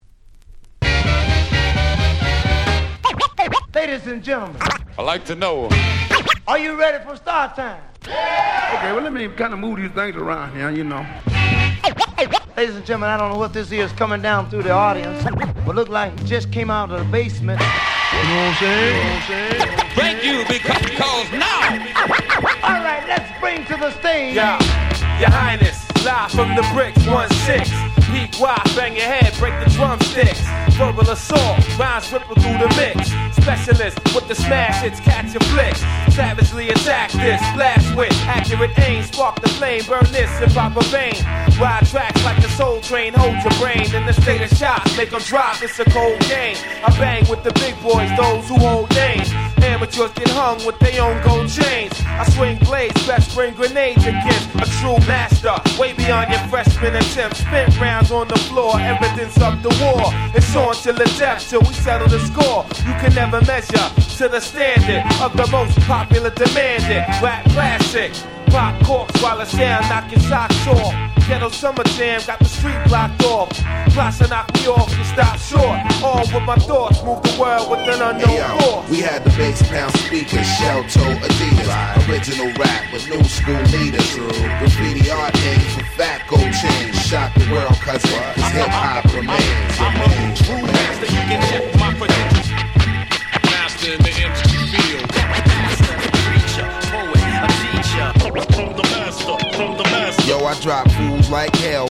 ピートロック Boom Bap ブーンバップ